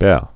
(bĕ, gbĕ)